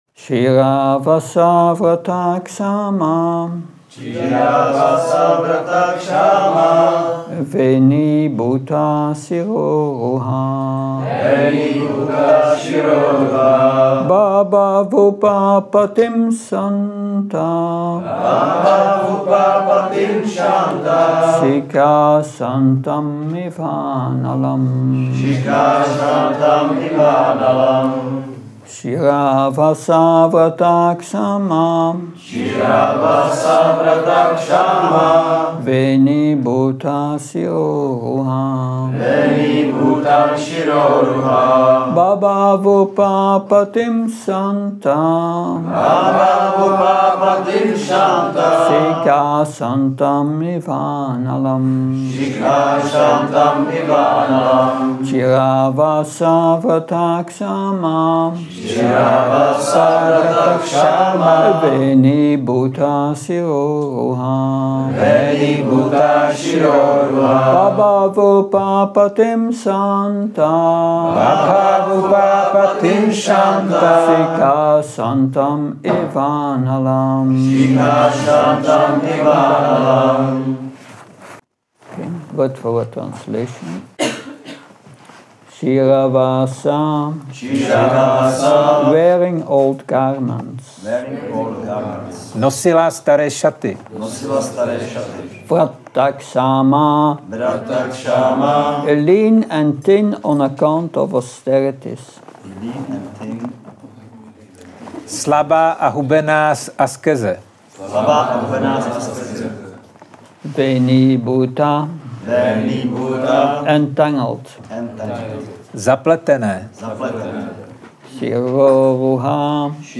Přednáška SB-4.28.44 Krišnův dvůr